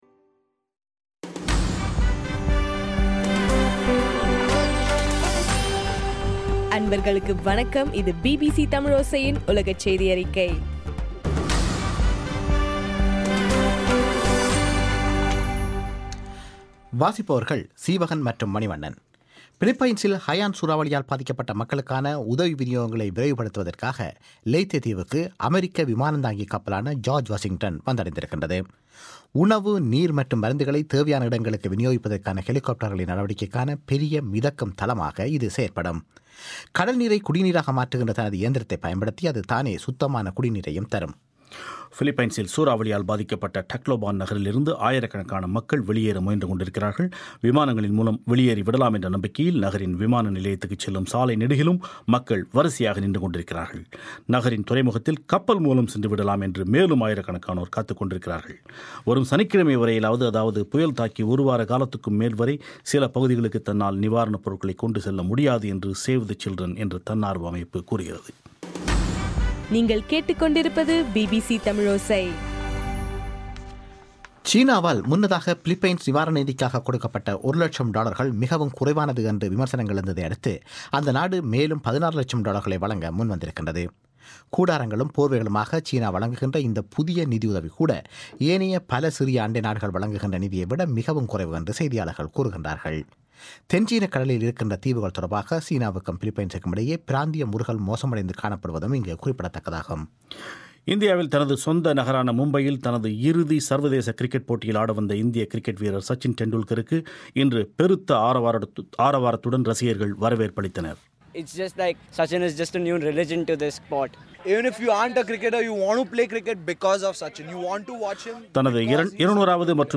நவம்பர் 14 2013 பிபிசி தமிழோசையின் உலகச்செய்திகள்